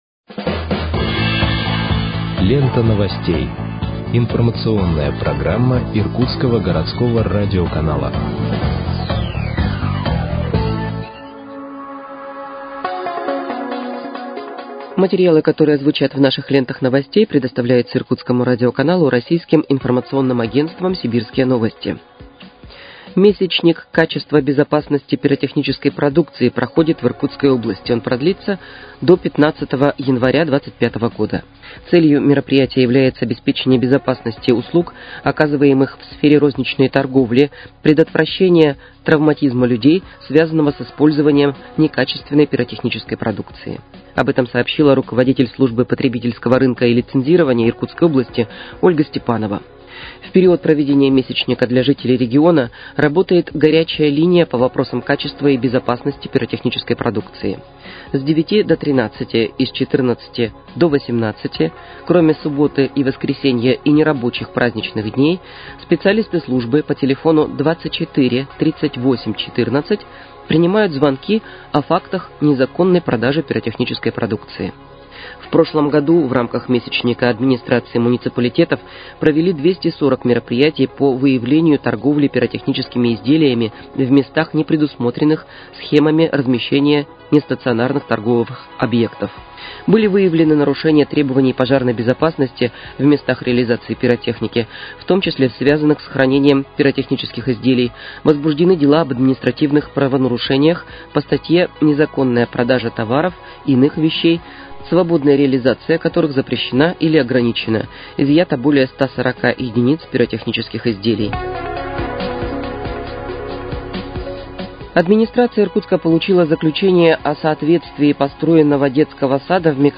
Выпуск новостей в подкастах газеты «Иркутск» от 17.12.2024 № 2